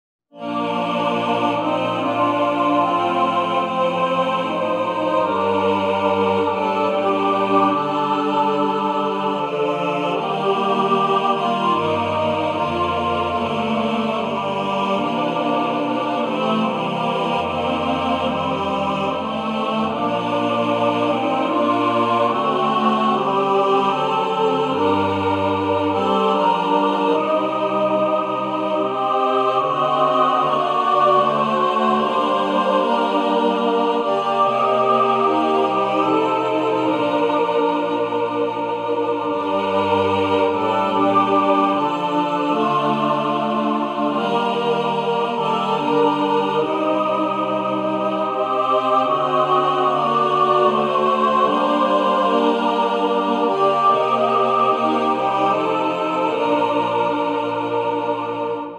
A lovely, Christmas lullaby hymn.